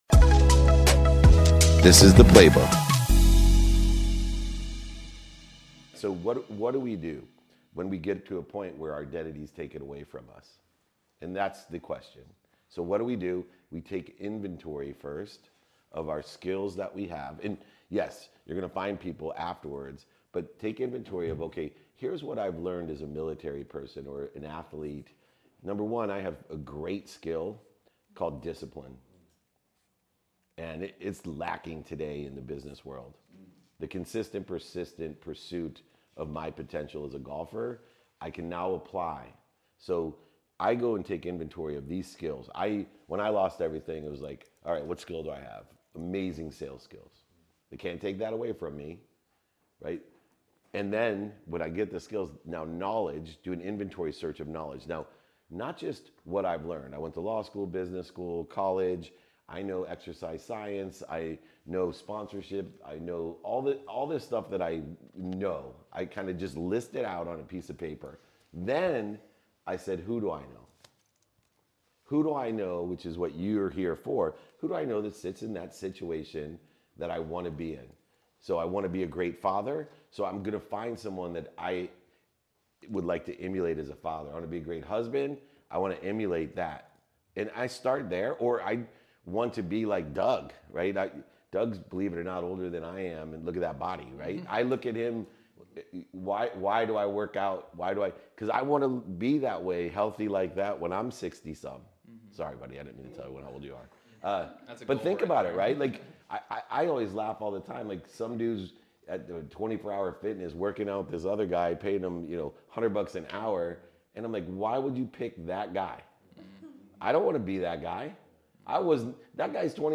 On today's episode, I had the pleasure of speaking about taking inventory of your skills, knowledge and desire to discover your identity in the business world at AlignCon 2023's mastermind. In this engaging dialogue I outlined the importance of asking for help from someone who sits in a situation you want to be in, how to align with what's doing well in today's economy, and why attention and intention creates coinciding with the universe.